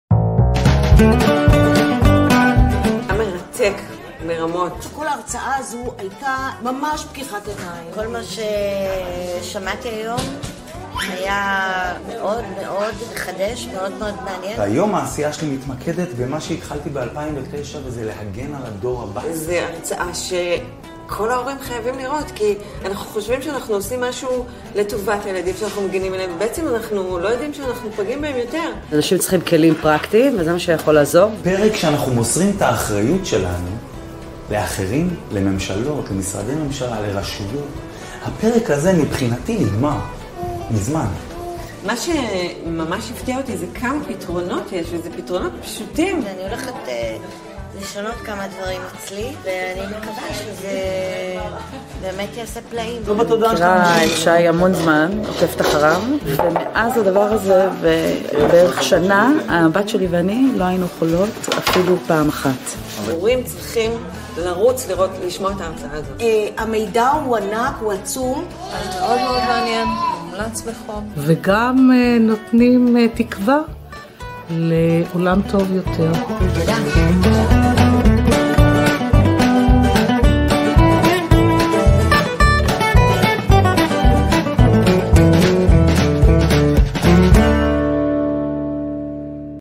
הרצאה להגן על הילדים ועלינו בעולם דיגיטלי מתועש